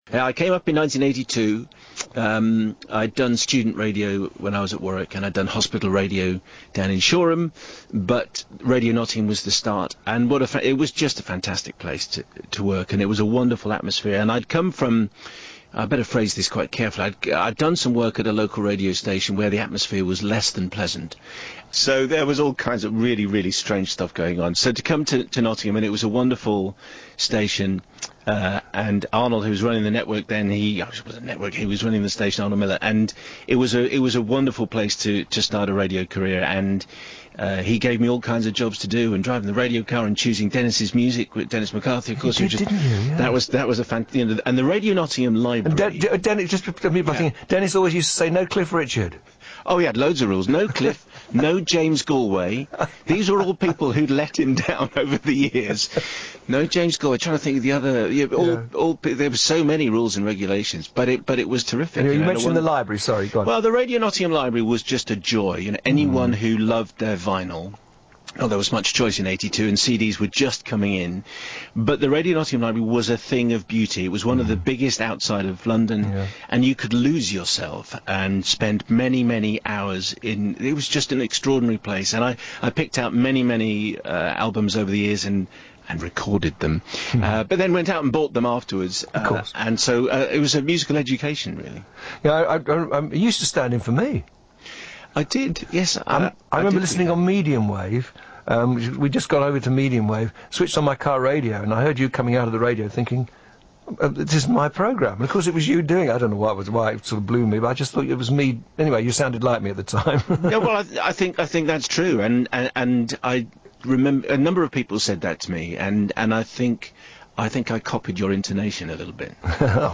Simon Mayo conversation